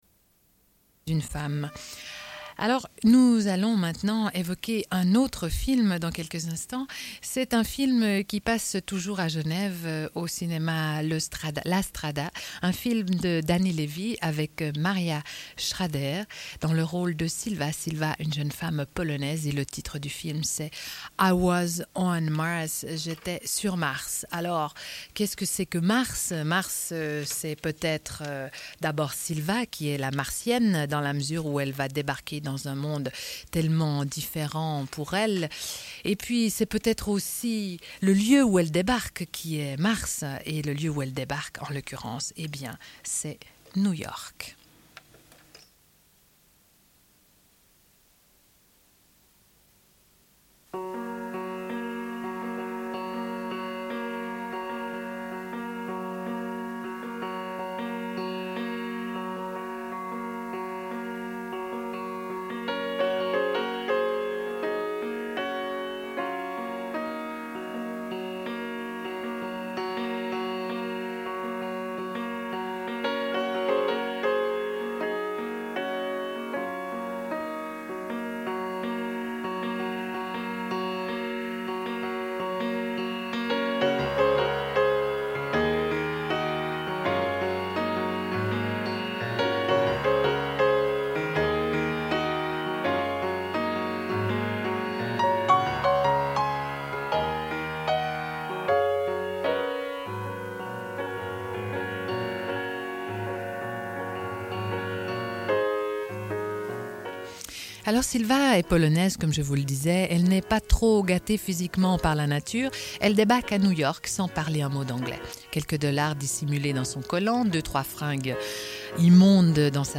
Une cassette audio, face B28:43
Radio Enregistrement sonore